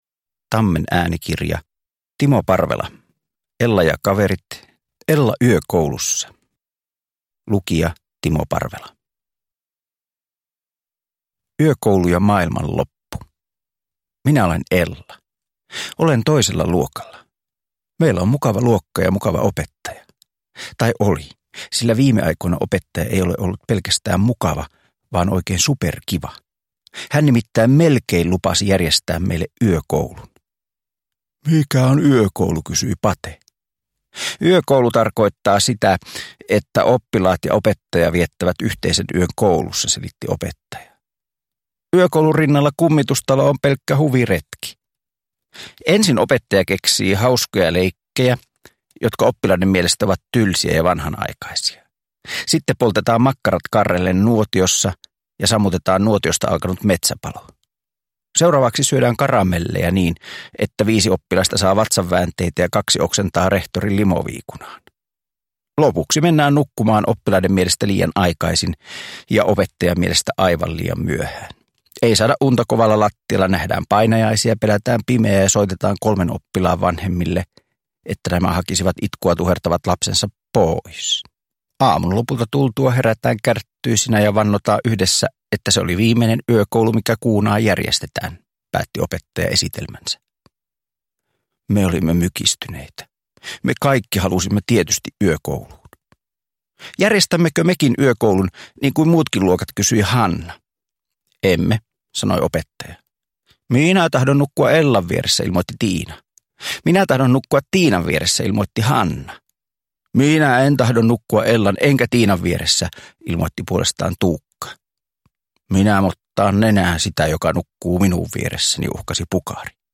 Ella yökoulussa – Ljudbok – Laddas ner
Uppläsare: Timo Parvela